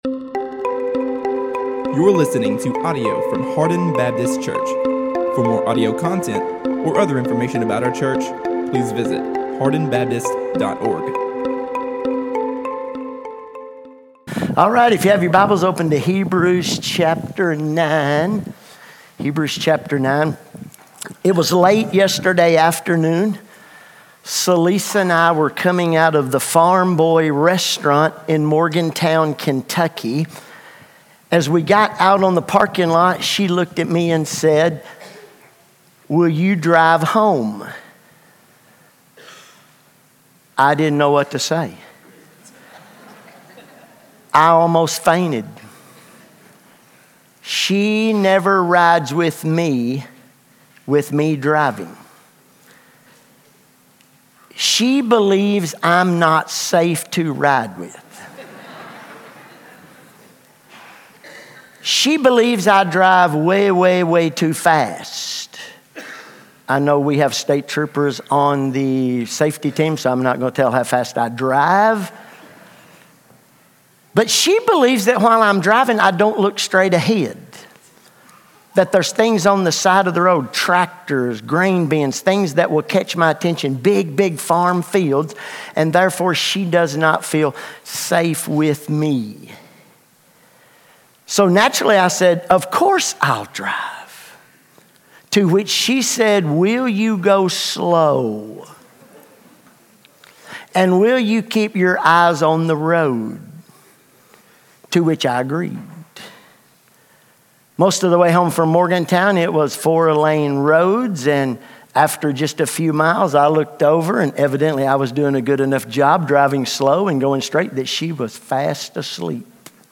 A message from the series "Hebrews 2025."